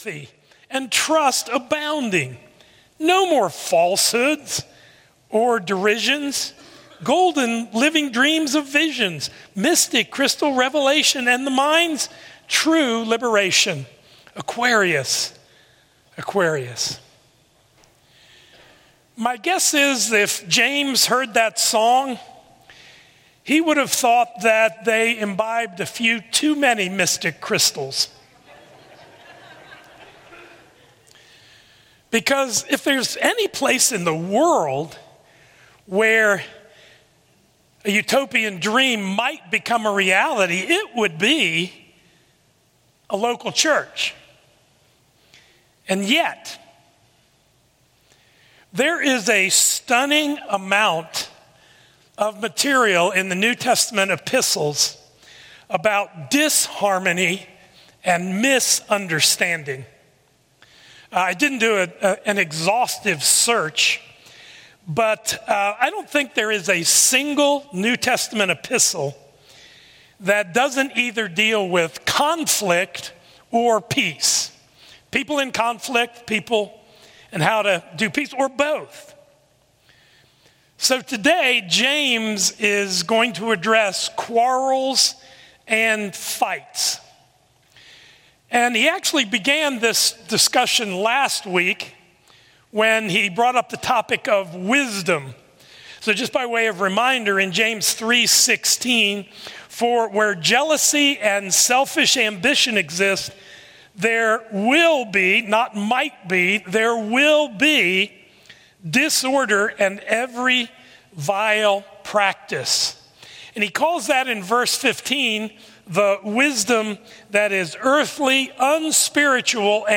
A message from the series "Good News."